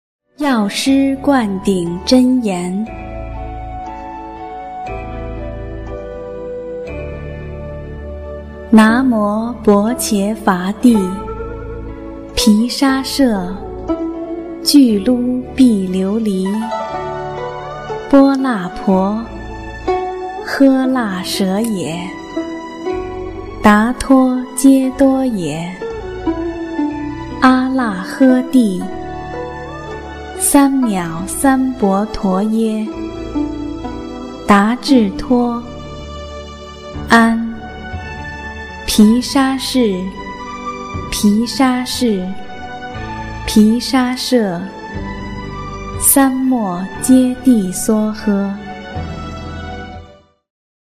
《药师灌顶真言》大字慢速教念